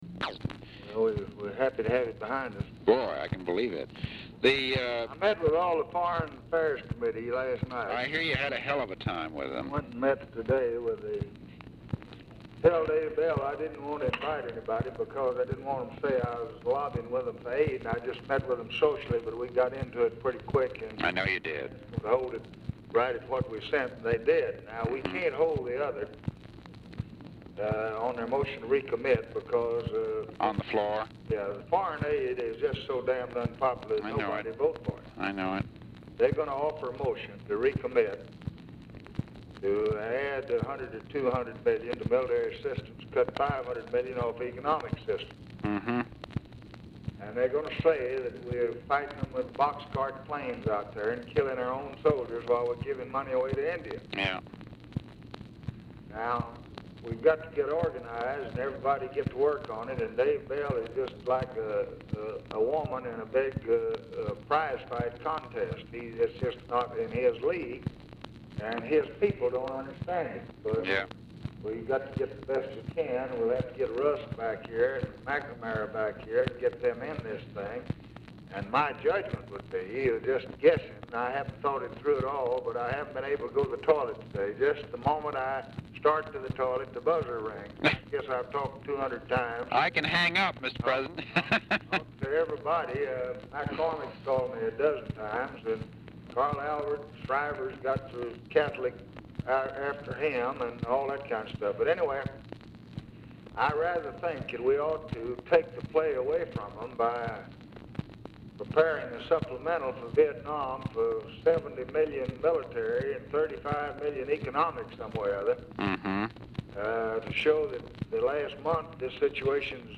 Telephone conversation # 3446, sound recording, LBJ and MCGEORGE BUNDY, 5/13/1964, 5:35PM | Discover LBJ
Format Dictation belt
Location Of Speaker 1 Oval Office or unknown location
Specific Item Type Telephone conversation